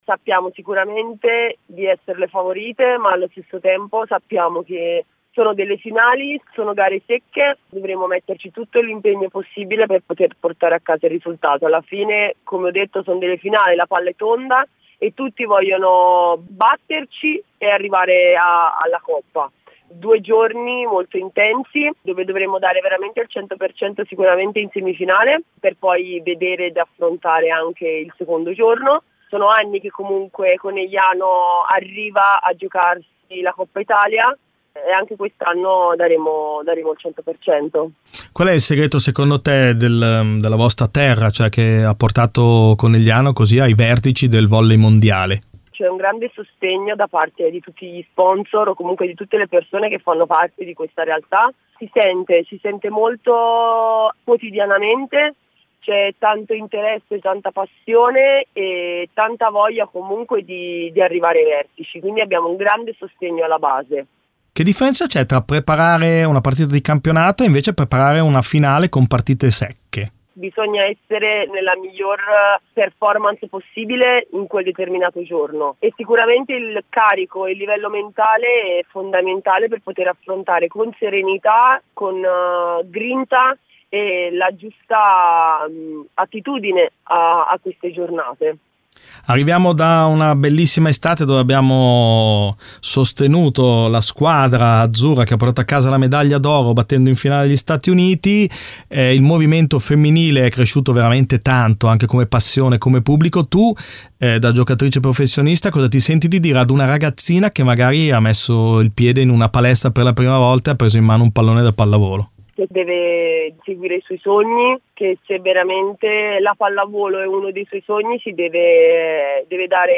Ai microfoni di Radio Bruno le voci di alcune delle protagoniste delle squadre che scenderanno in campo per la vittoria finale.